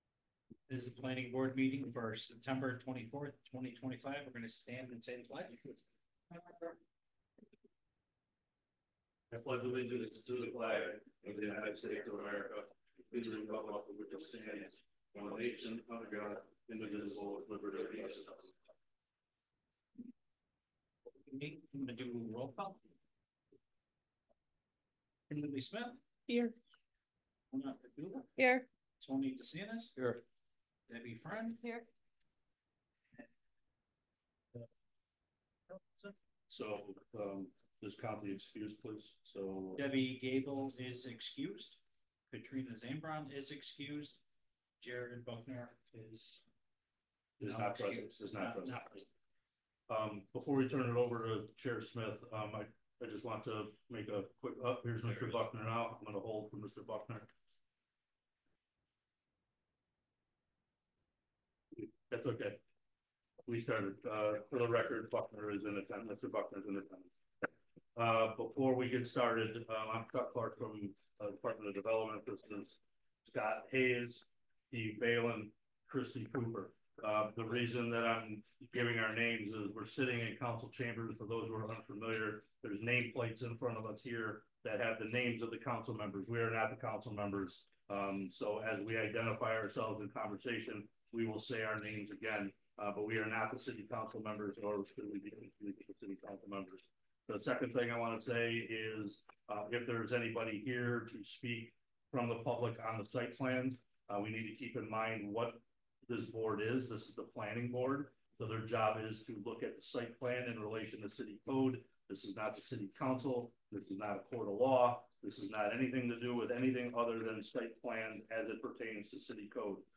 Lackawanna-Planning-Board-Meeting-9-25-25.mp3